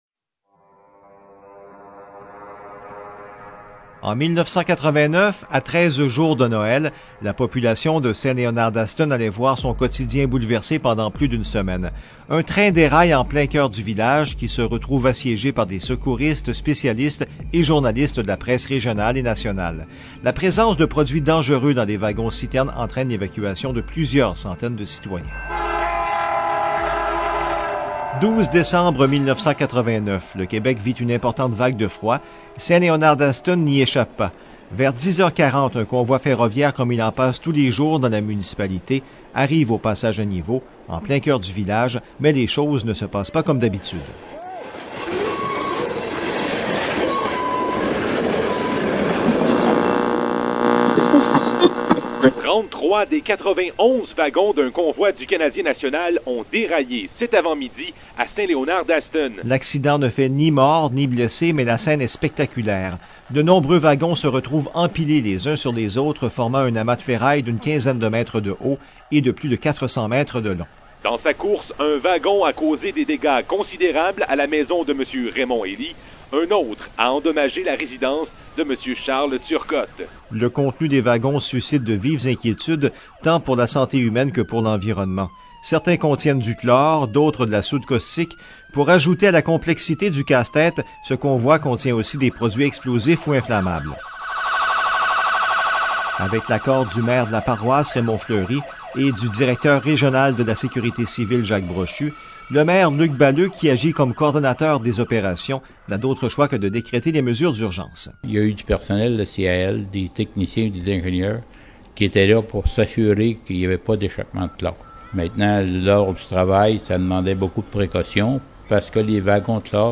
Reportage complet